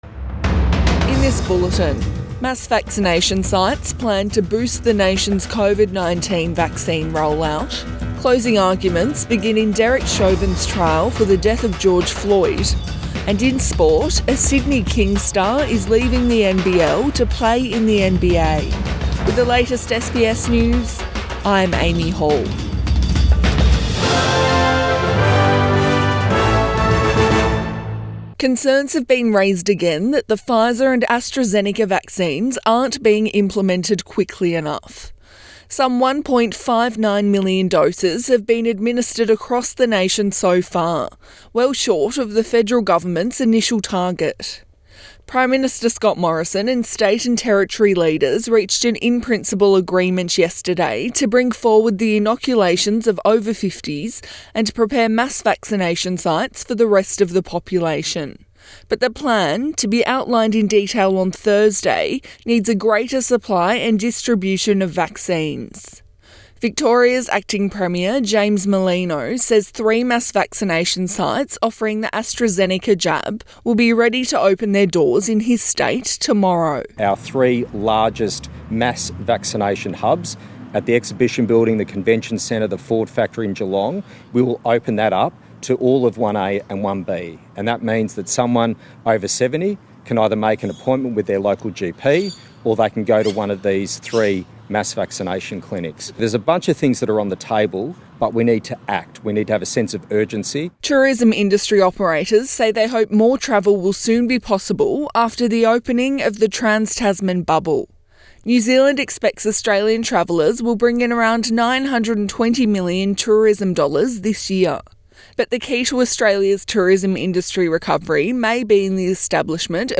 AM bulletin 20 April 2021